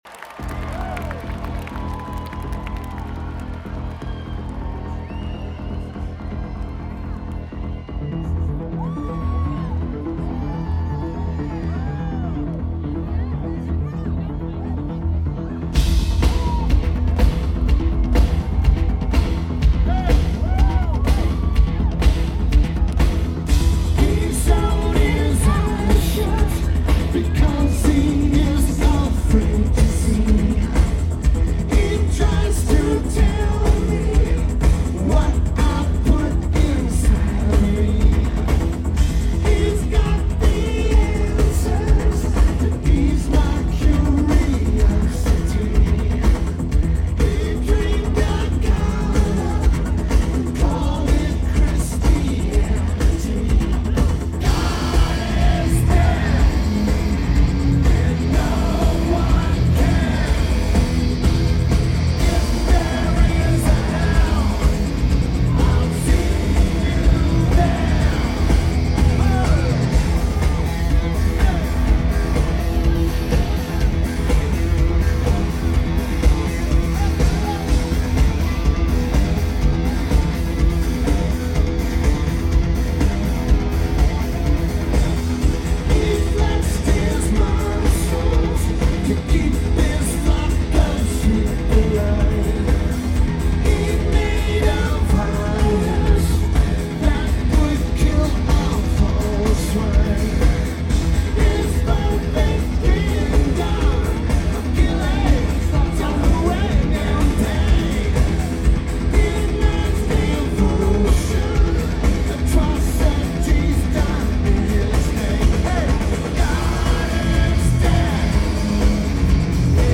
McMenamins Edgefield
Lineage: Audio - AUD (AT853s + CA-9200 + Sony PCM-A10)
Notes: Slightly left center, just behind FOH.
A really good tape!